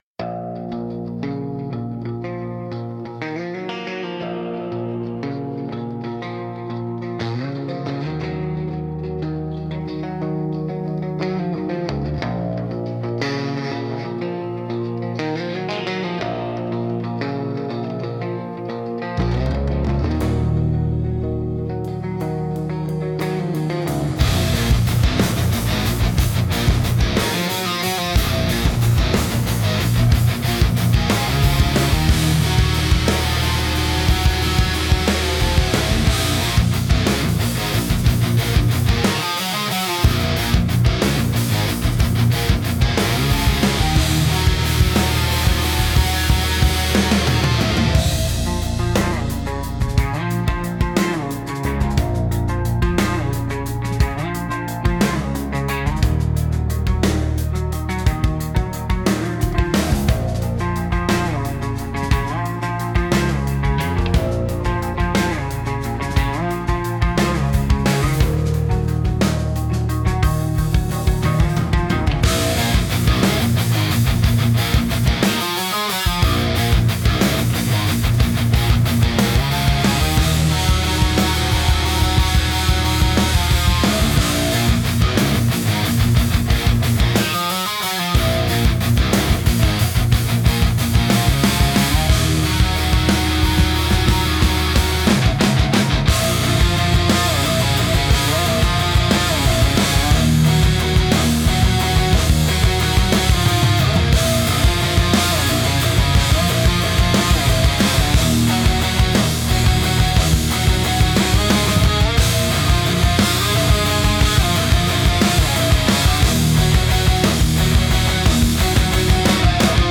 Instrumental - Dusk in Open D